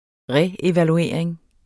Udtale [ ˈʁεevaluˌeˀɐ̯eŋ ]